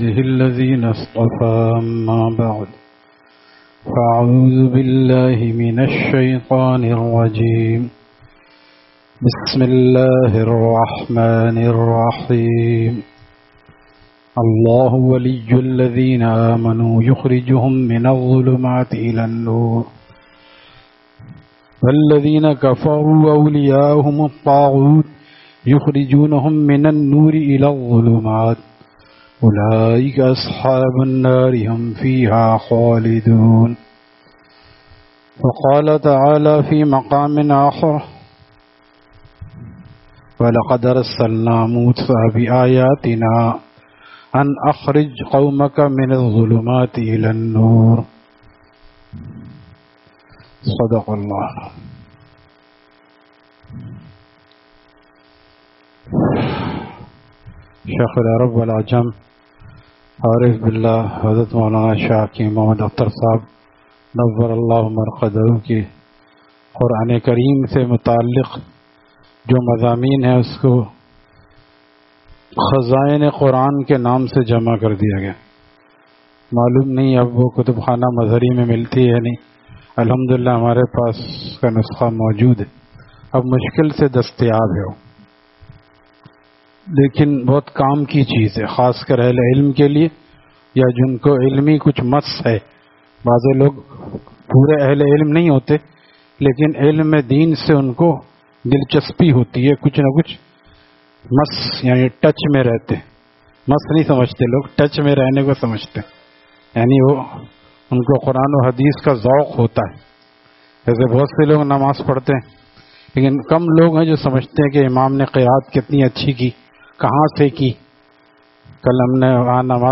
Saturday Markazi Bayan at Jama Masjid Gulzar e Muhammadi, Khanqah Gulzar e Akhter, Sec 4D, Surjani Town